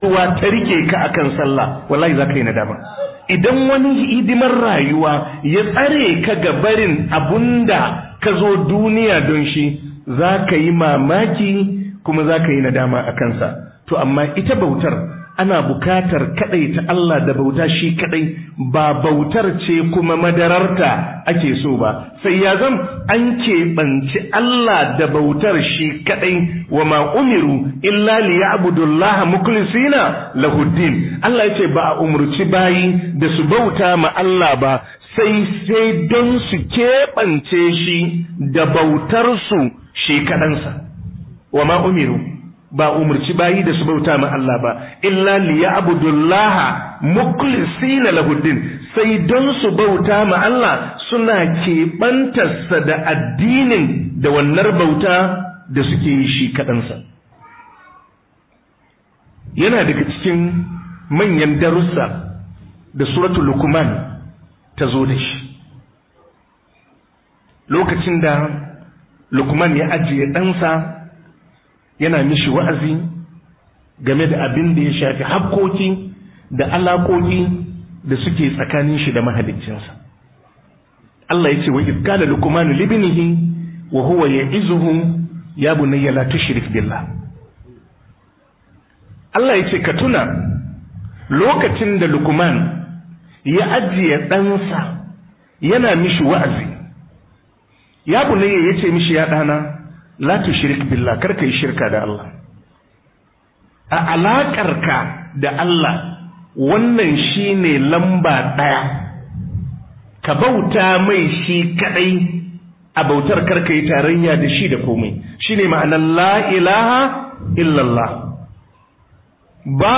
Tsakanin Bawa Da Allah (Muhadara Daga Abuja)